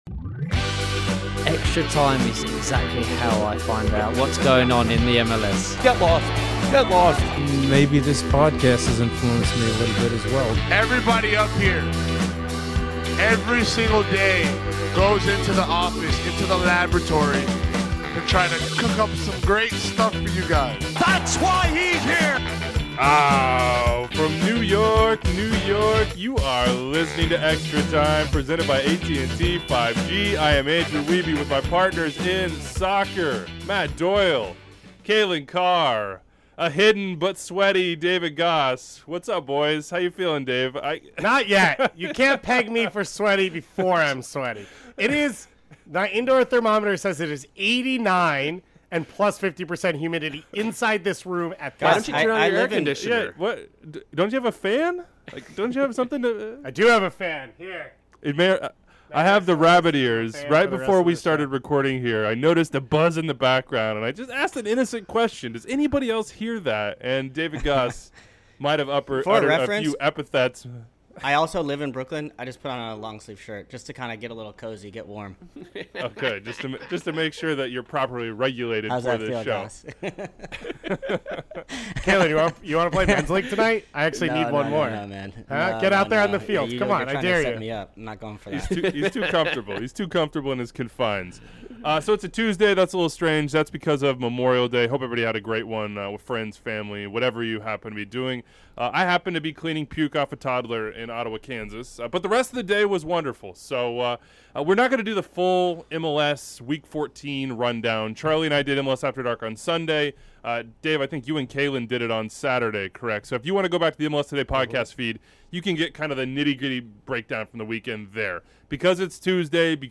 The Monday crew sits down to hand out grades to every single team, plus talk about Charlotte's big morning (adios, Miguel Angel Ramirez!).